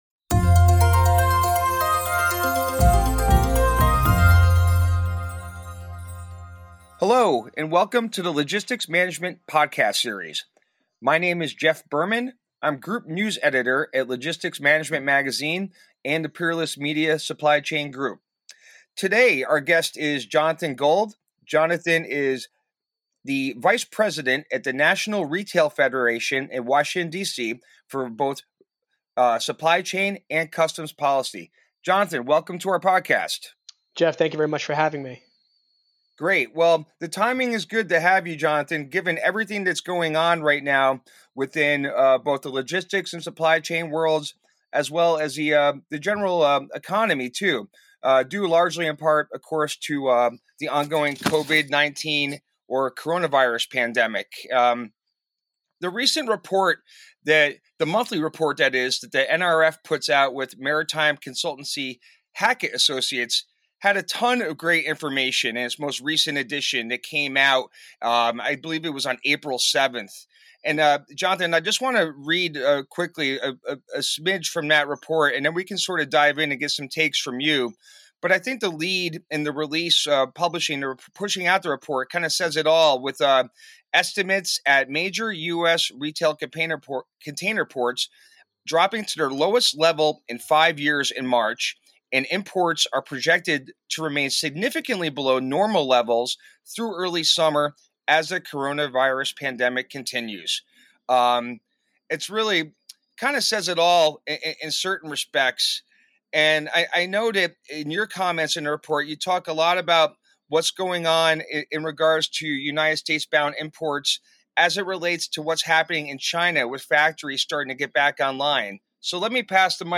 Join The Logistics Management Podcast as we dive into the world of logistics with in-depth conversations featuring industry professionals on the front lines of supply chain innovation.